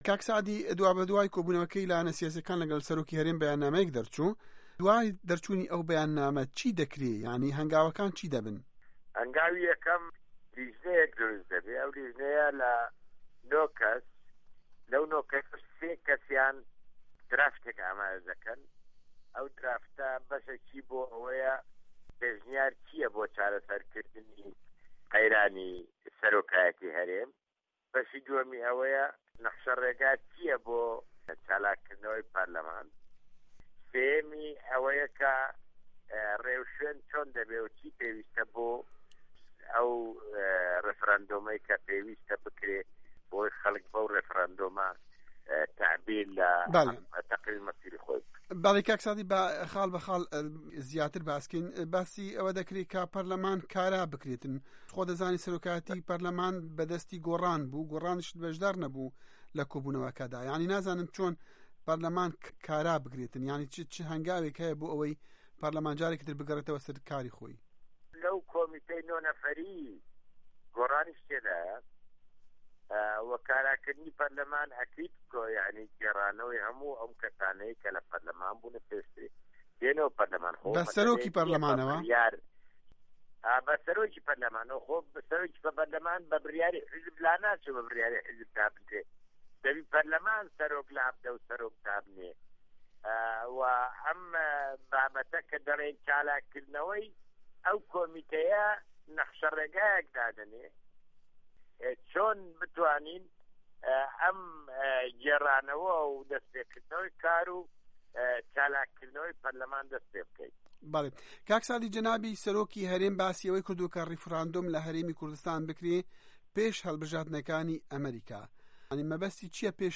وتووێژ